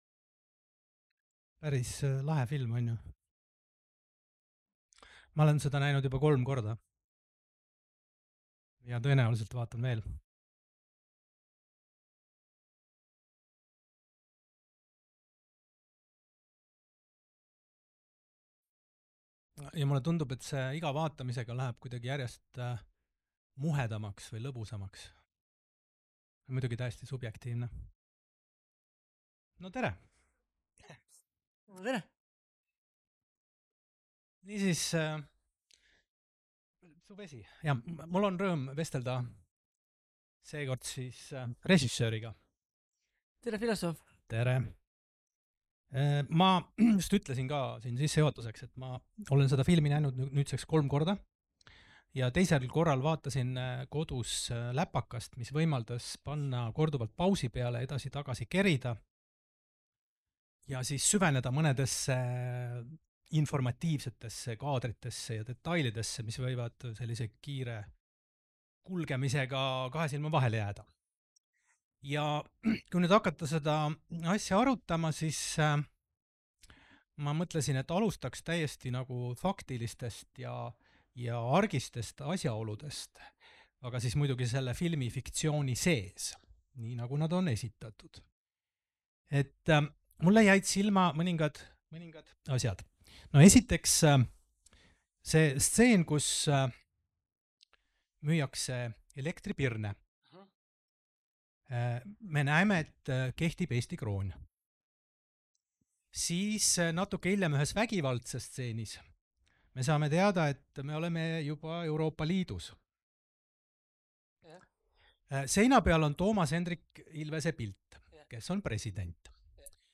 NB! Vestluse salvestus sisaldab sisurikkujaid.